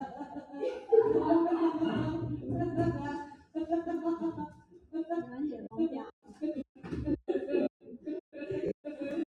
Angel Laugh Botão de Som